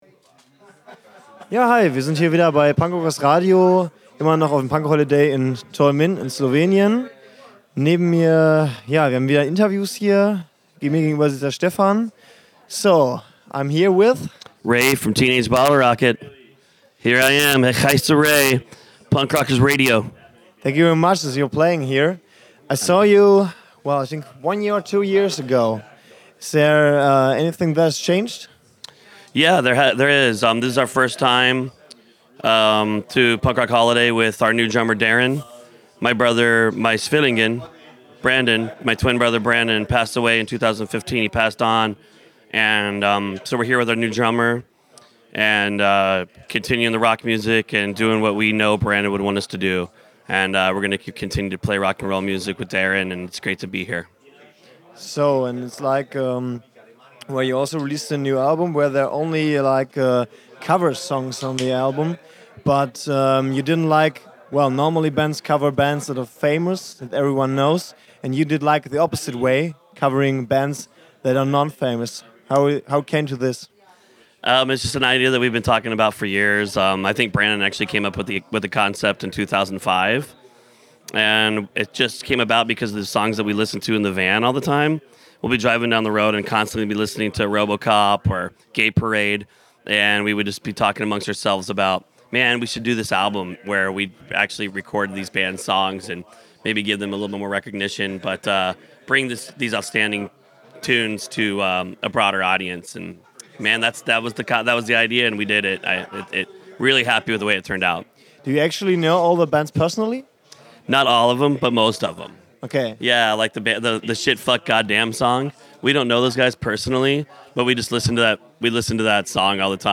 Letzte Episode Interview mit Teenage Bottlerocket @ Punk Rock Holiday 1.7 9.
interview-mit-teenage-bottlerocket-punk-rock-holiday-1-7-mmp.mp3